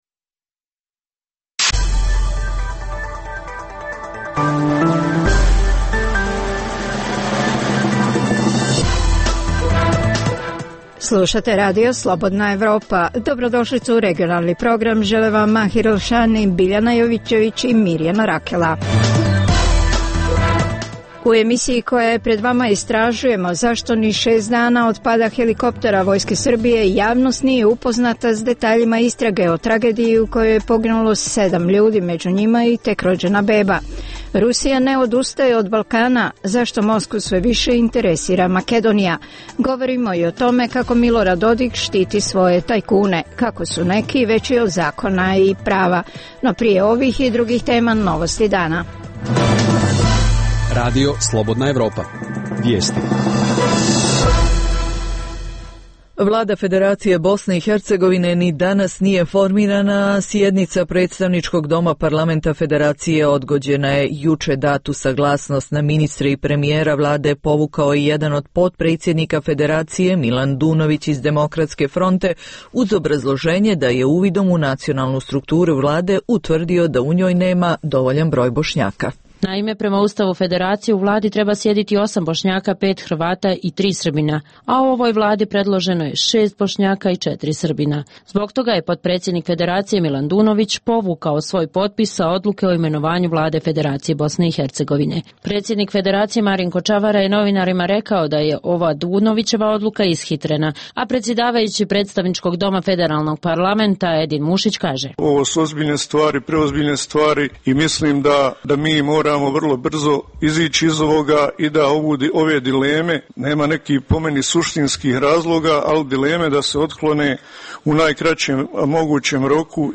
Dnevna informativna emisija Radija Slobodna Evropa o događajima u regionu i u svijetu.